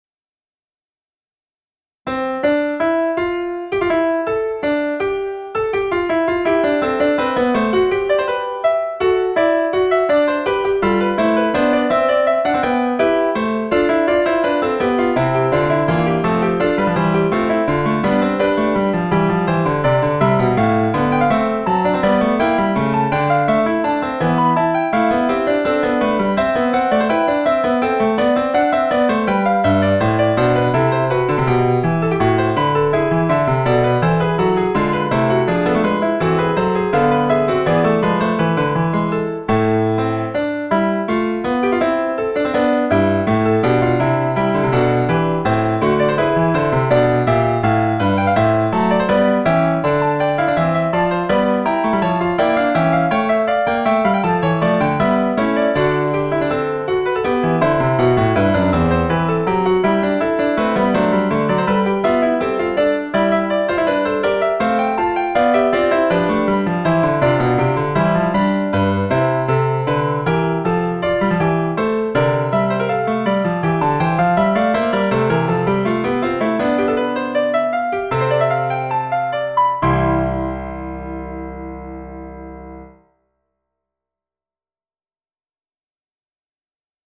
compressed presentation: Stereoposition add
The original instrumentation is a joint from 5 voices.